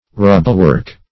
Meaning of rubblework. rubblework synonyms, pronunciation, spelling and more from Free Dictionary.
Search Result for " rubblework" : The Collaborative International Dictionary of English v.0.48: Rubblework \Rub"ble*work`\, n. Masonry constructed of unsquared stones that are irregular in size and shape.